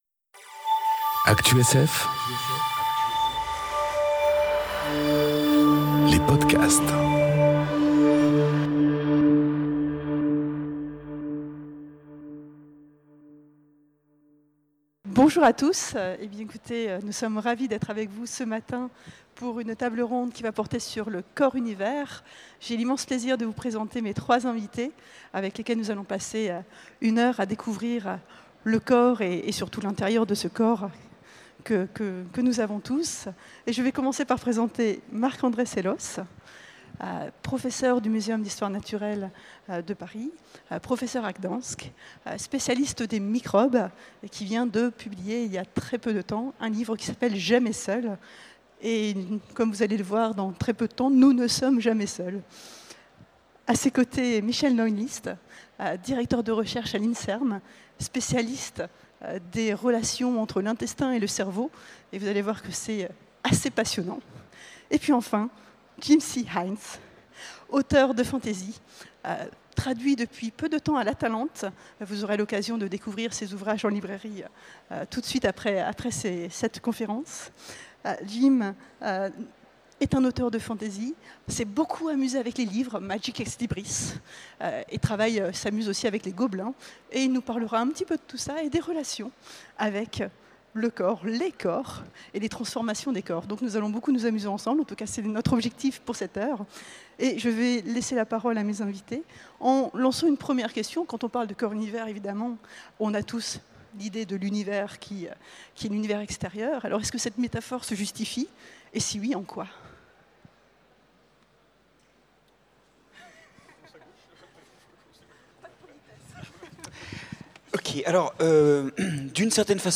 Conférence Le corps univers enregistrée aux Utopiales 2018